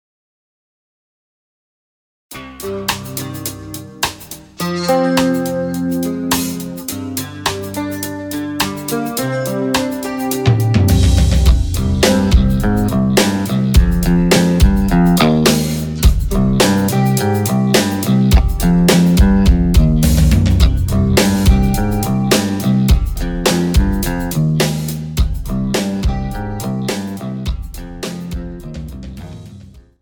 • Type : Instrumental
• Bpm : Allegretto
• Genre : Rock